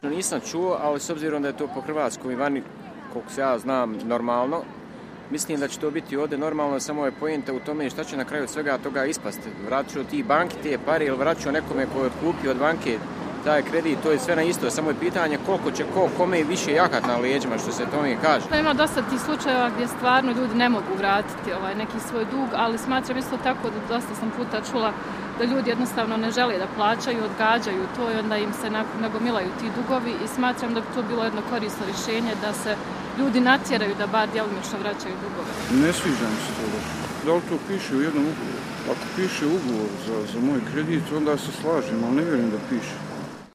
Anketa Sarajevo krediti